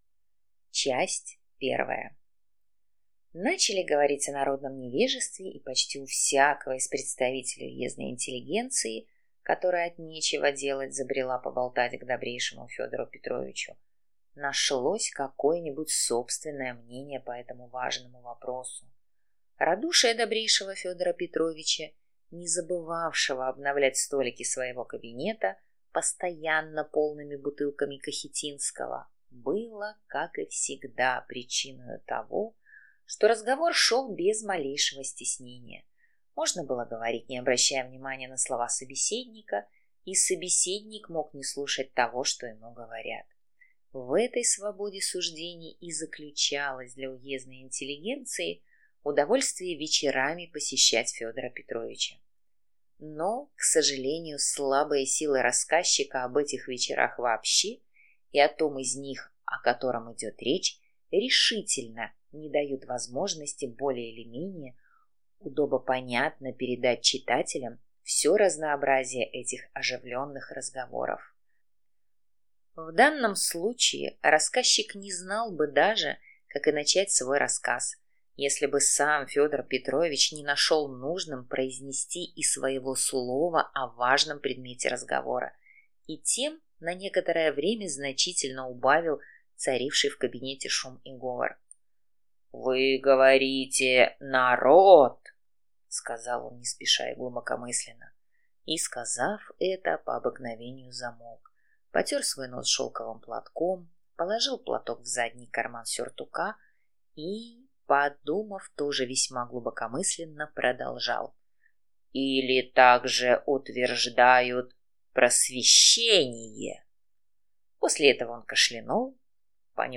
Аудиокнига За малым дело | Библиотека аудиокниг